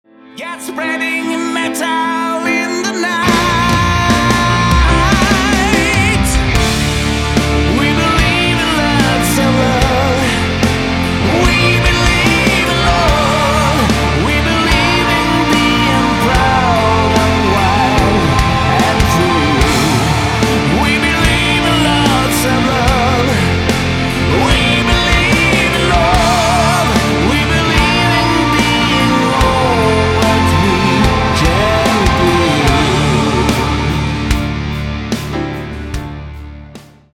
• Качество: 192, Stereo
heavy Metal
power metal